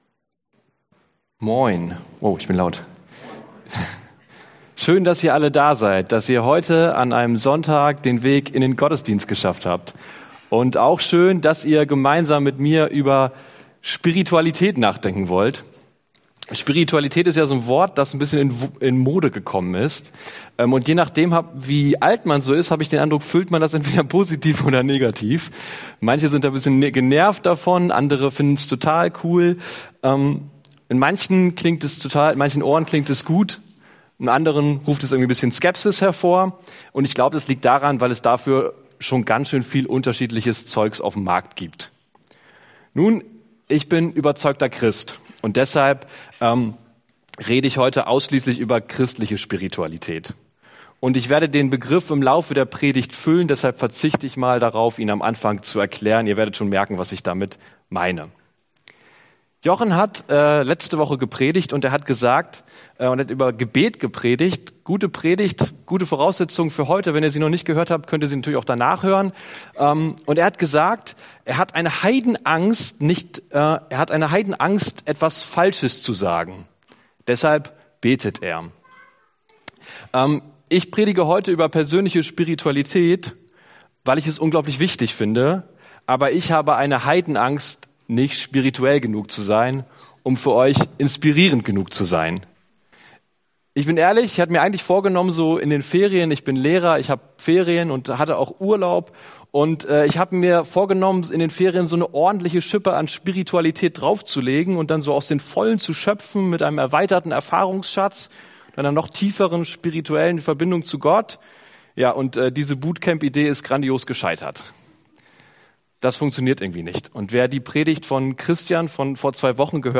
Serie: Dein Sommer mit Gott Dienstart: Predigt Themen